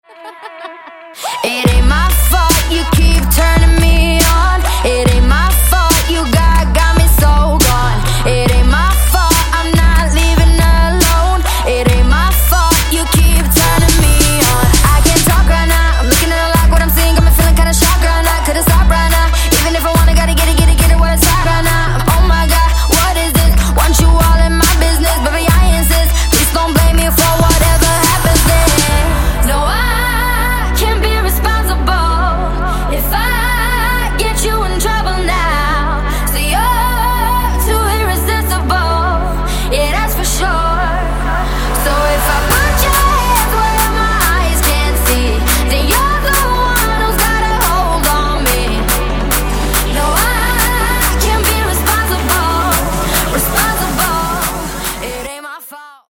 • Качество: 128, Stereo
поп
женский вокал
dance
Electronic
красивый женский голос
vocal